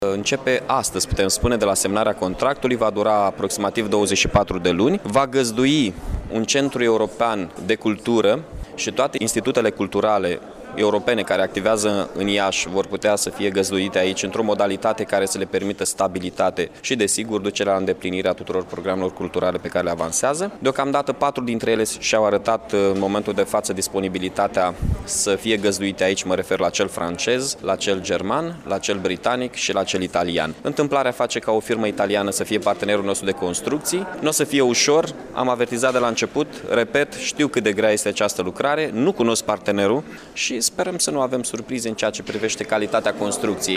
Lucrările de reabilitare şi de consolidare, în valoare de 16 milioane de lei, se vor derula pe parcursul a doi ani, după cum a precizat primarul Iaşului, Mihai Chirica: